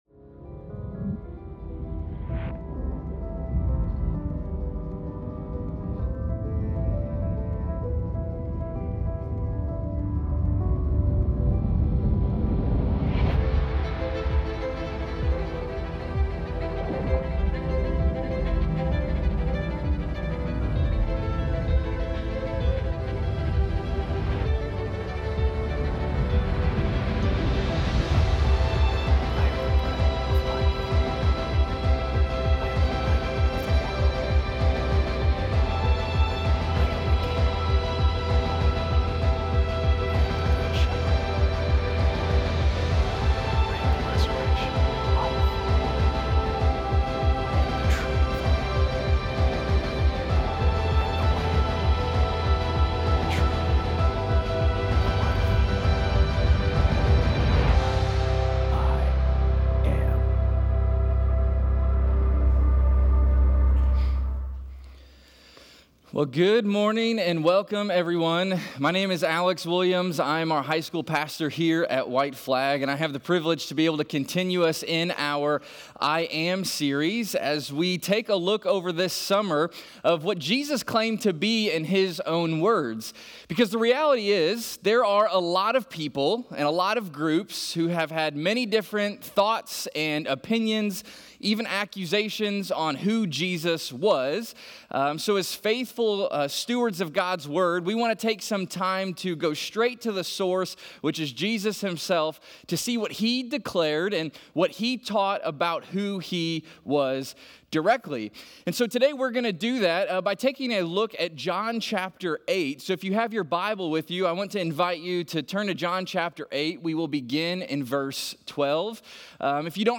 Sermons
i-am-john-8-sermon-audio.mp3